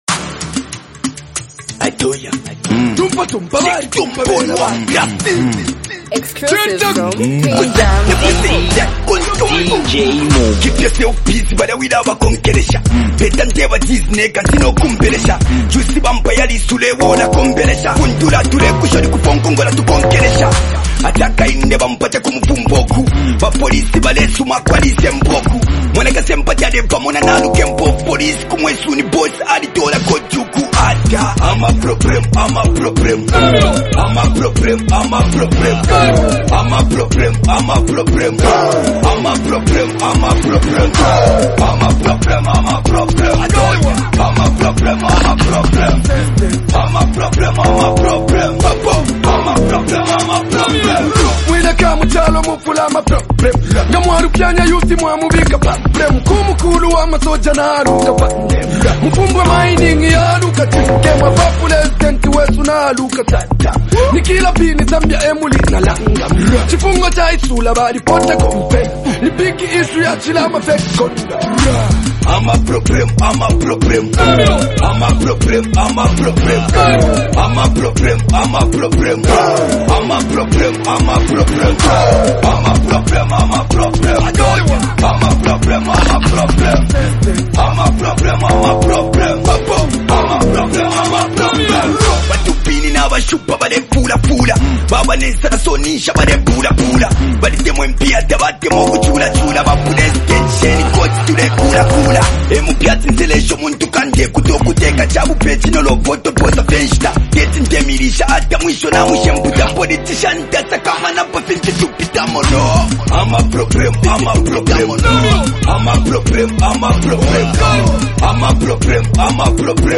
street anthem